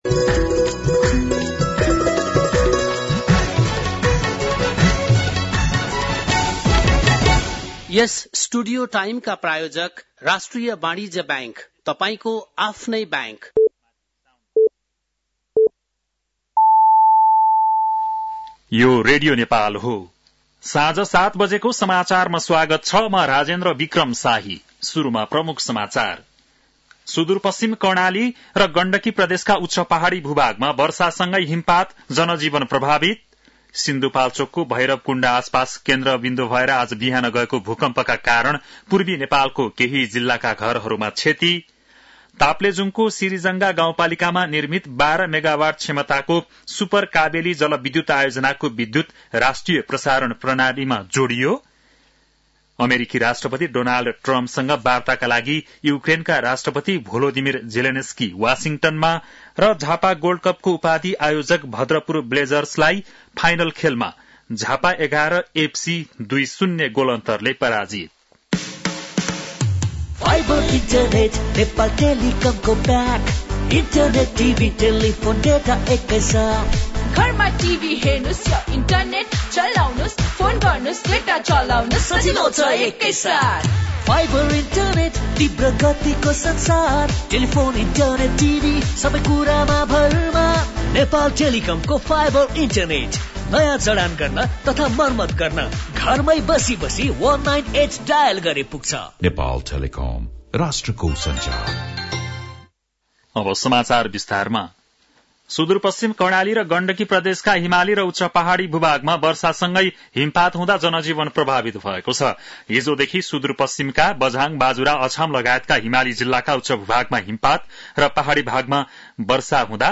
बेलुकी ७ बजेको नेपाली समाचार : १७ फागुन , २०८१
7-PM-Nepali-NEWS-11-16.mp3